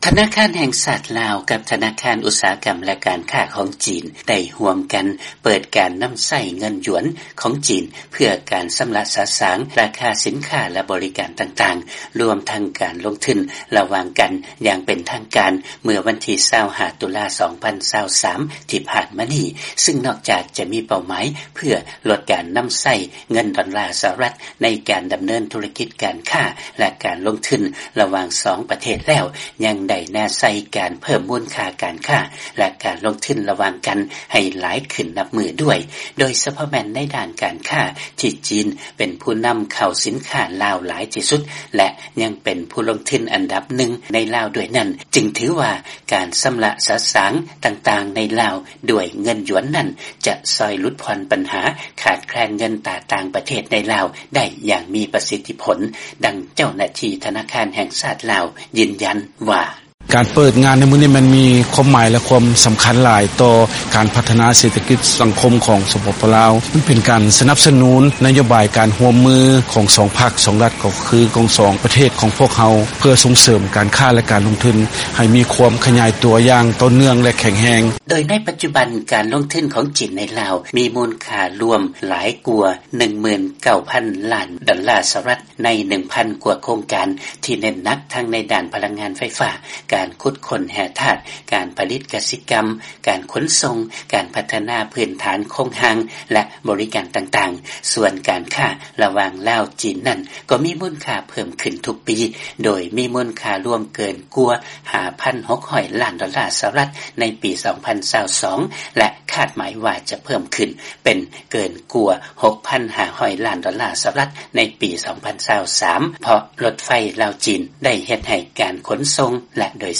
ຟັງລາຍງານ ທະນາຄານລາວ ແລະ ຈີນ ເລີ່ມການນຳໃຊ້ເງິນຢວນໃນການຊຳລະສະສາງລາຄາສິນຄ້າ ແລະ ບໍລິການຕ່າງໆ